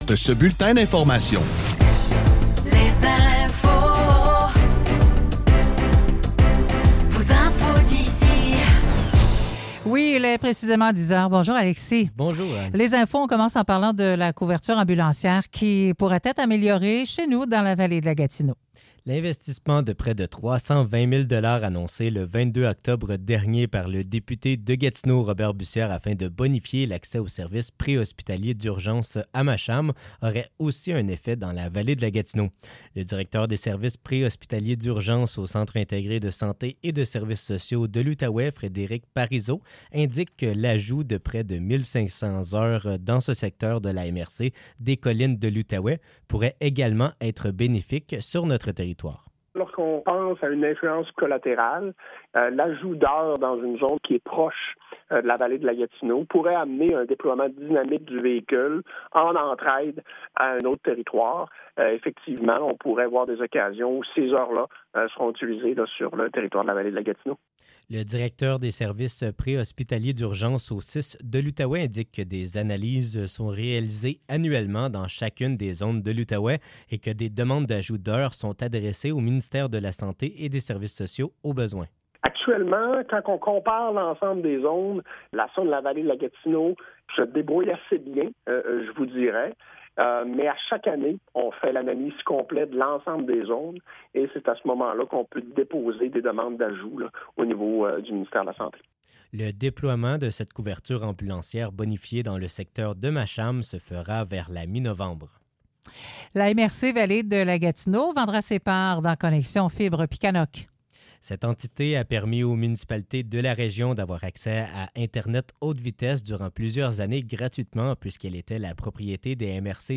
Nouvelles locales - 6 novembre 2024 - 10 h